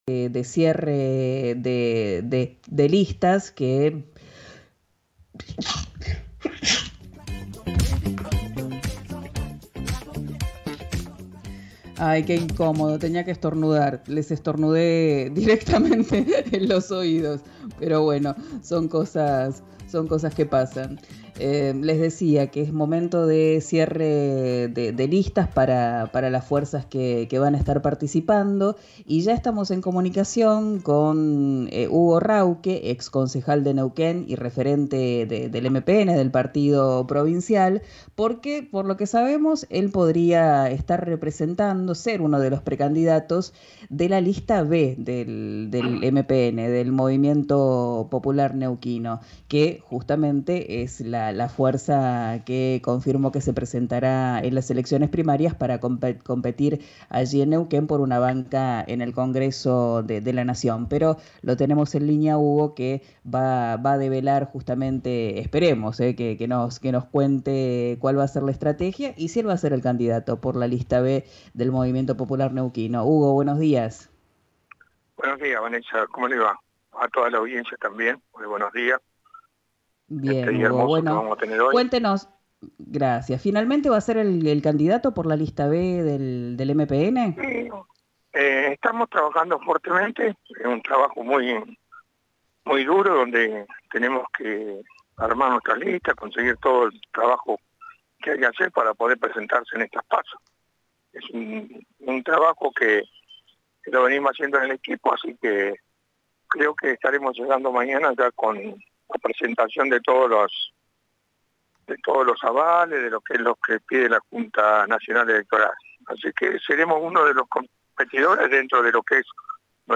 El exconcejal de Neuquén encabezará la lista 'B' del partido provincial. En diálogo con RÍO NEGRO RADIO, apuntó contra el actual gobierno y respaldó a Rolando Figueroa.